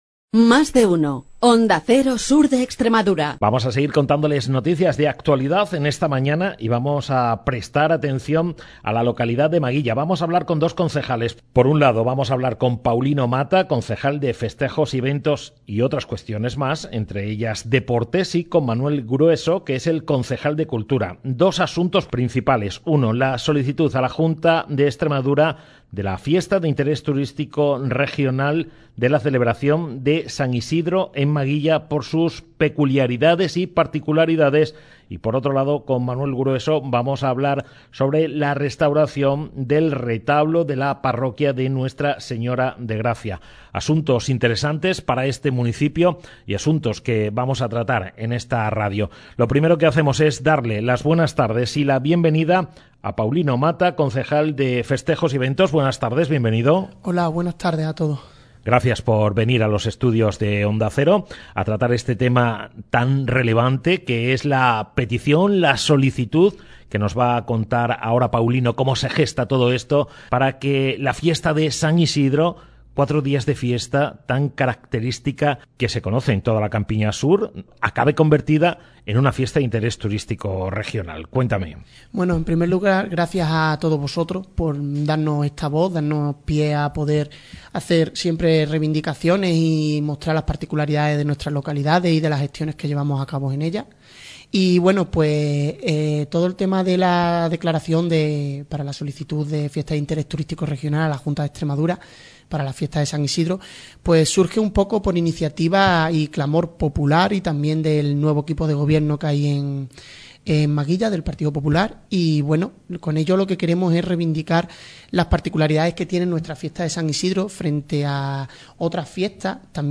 El pleno del Ayuntamiento de Maguilla ha aprobado la propuesta de la Asociación Cultural Pro San isidro para poder iniciar los trámites administrativos necesarios con la Consejería de Cultura de la Junta de Extremadura con el fin de declarar las fiestas patronales de San Isidro como Fiesta de Interés Turístico Regional, según ha contado en Onda Cero el concejal de Festejos, Paulino Mata.
Manuel Grueso, concejal de Cultura ha contado en Onda Cero la situación en la que se encuentra el retablo y la intervención a la que tendrá que someterse.